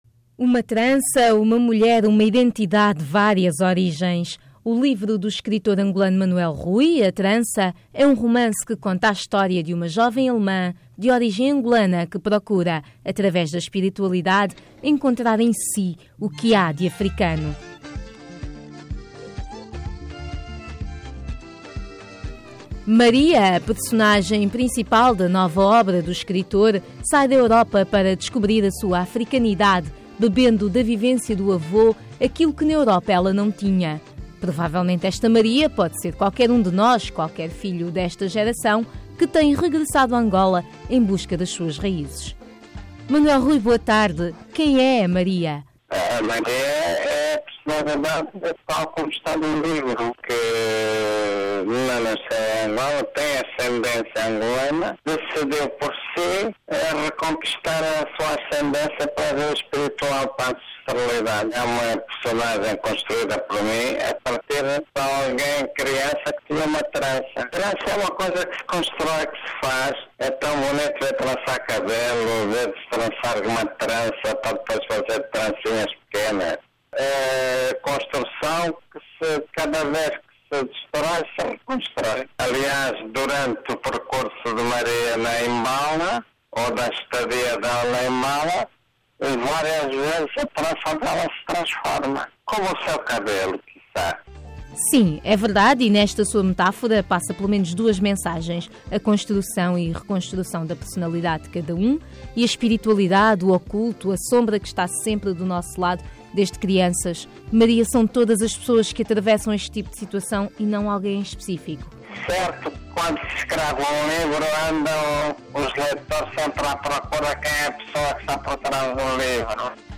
A Trança - Entrevista com Manuel Rui - 10:54